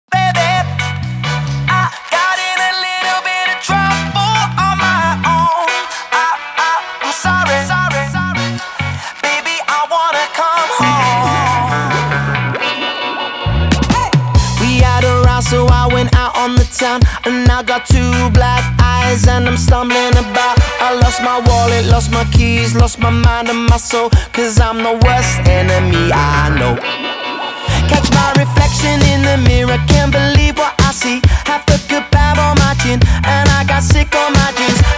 2025-07-24 Жанр: Поп музыка Длительность